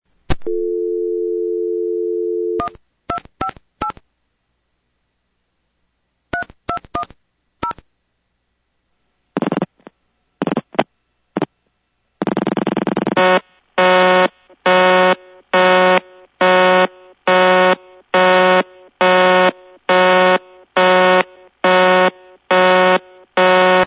These were made over the Collector’s network  (C-Net) using an Analog Telephone Adapter (ATA) via the Internet.